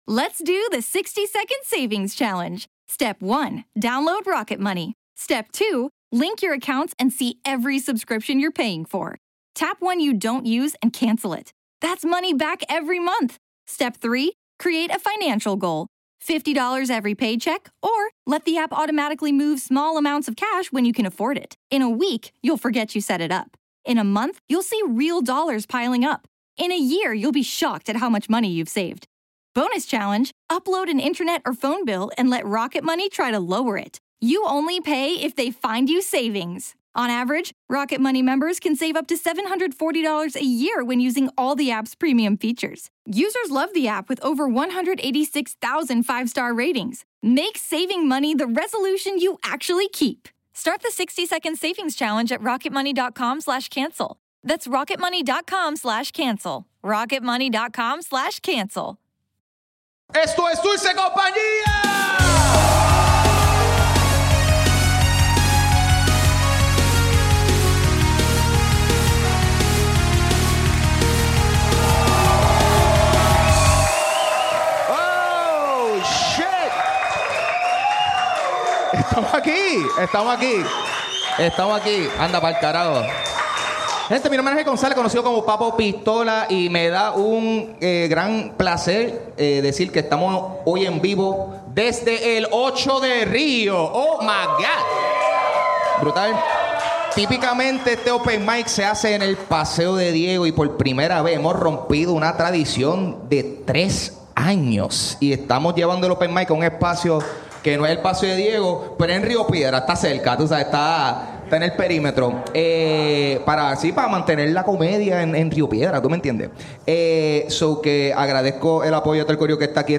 Hacemos el open mic por primera vez en El Ocho con lectura de horóscopo incluida.